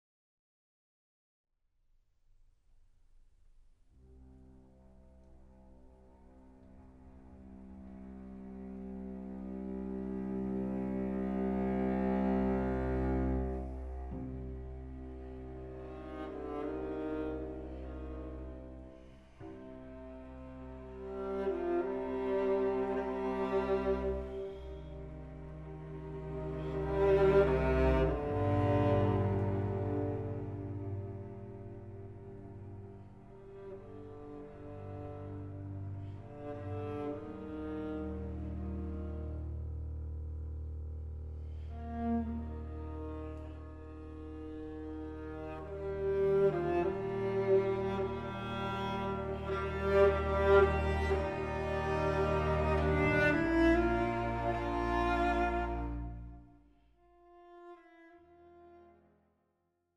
Voicing: Full Orche